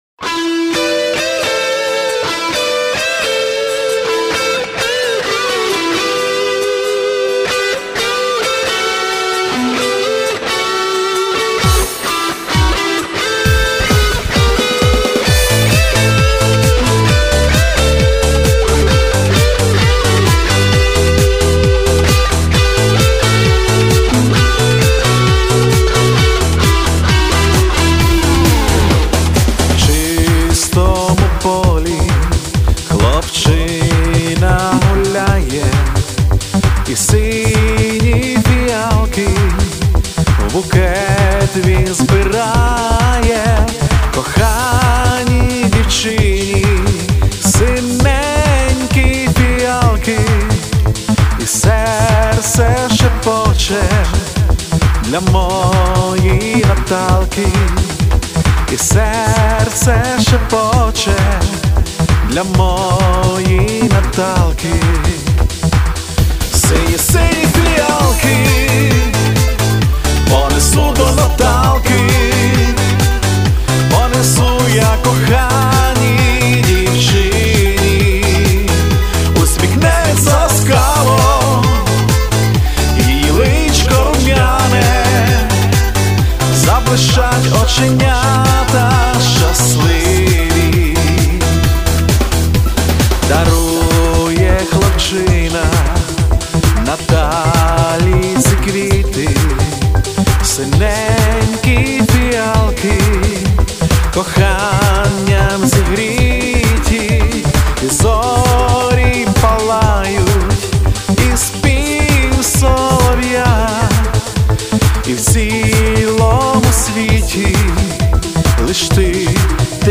Жив звук крім барабанів, Бубни з ПА 50 Все остальне "Живяк"